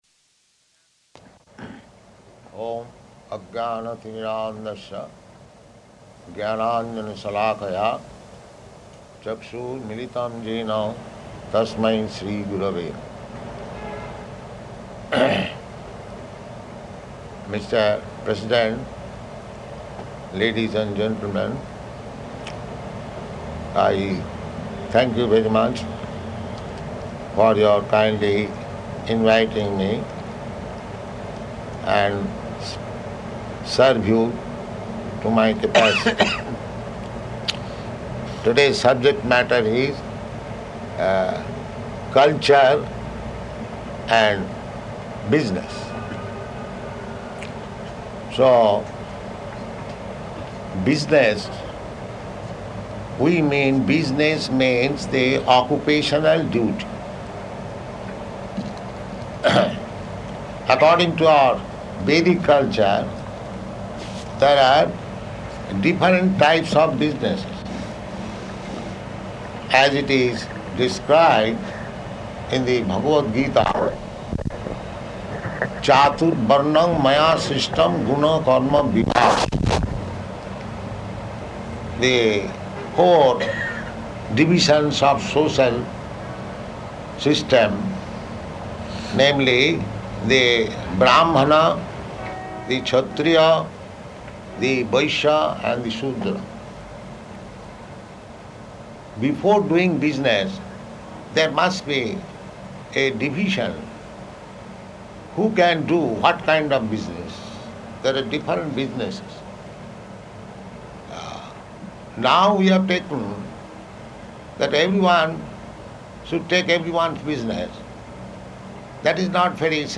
Lecture at Bhārata Chamber of Commerce
Type: Lectures and Addresses